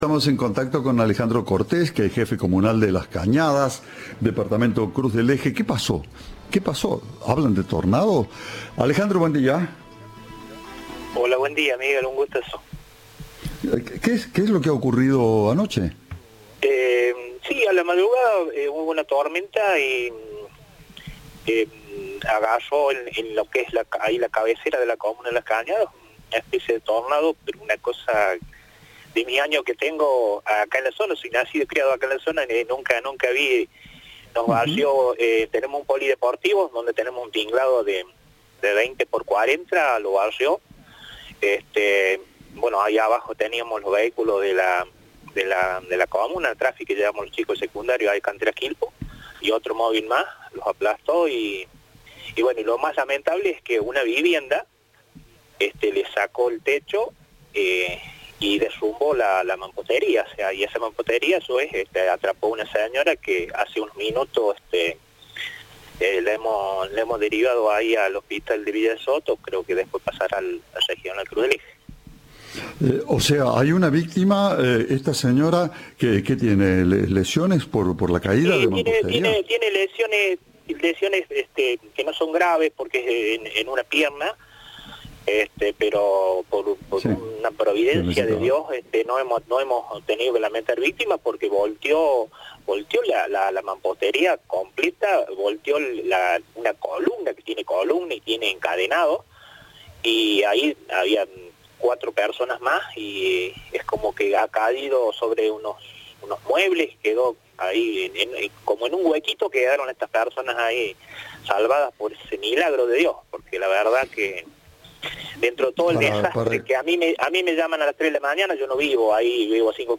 El jefe comunal Alejandro Cortés, en diálogo con Cadena 3, aseguró que jamás vio algo semejante en su pueblo.
El ministro de Desarrollo Social, Carlos Massei, dijo a Cadena 3 que se está realizando un relevamiento de los daños.